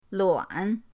luan3.mp3